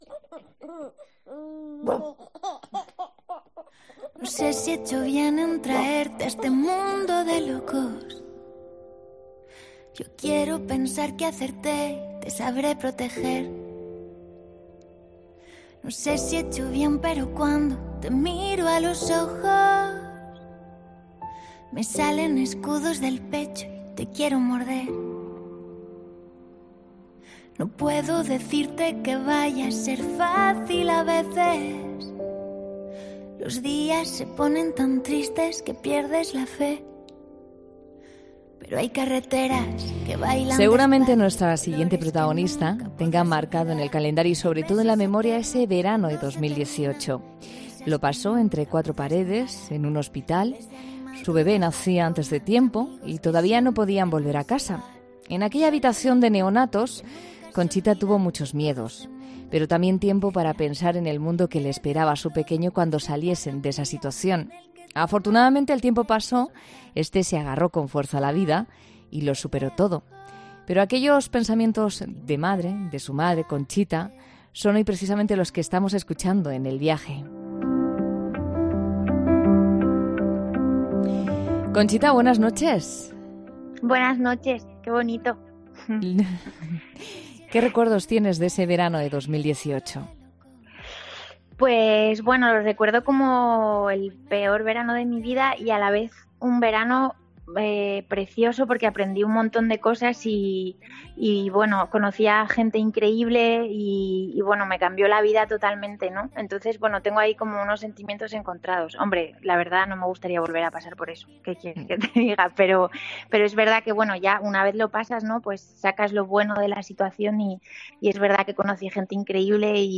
Una preciosa letra que ha querido compartir ante los micrófonos de 'La Noche' de COPE .